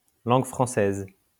French (français [fʁɑ̃sɛ] or langue française [lɑ̃ɡ fʁɑ̃sɛːz]